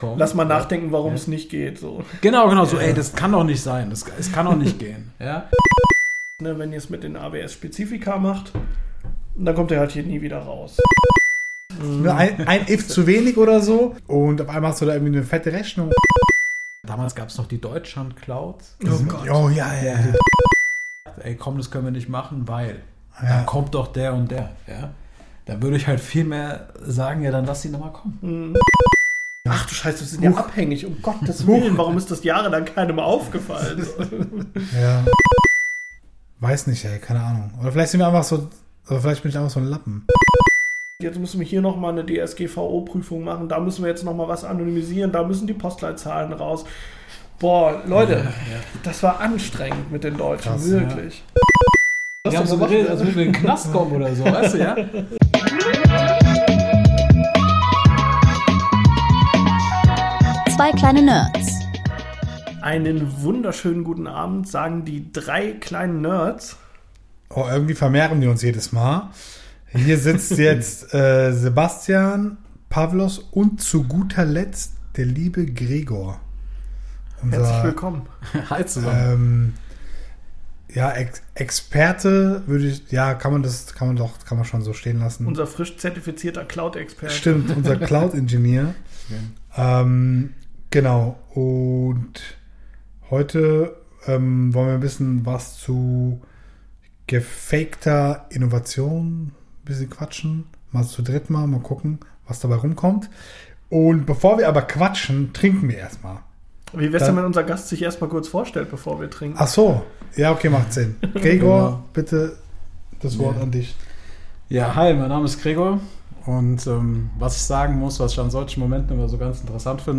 Freut euch auf ein offenes, kontroverses und humorvolles Gespräch, das zum Nachdenken anregt und spannende Einblicke bietet.